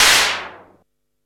SIMMONS SDS7 5.wav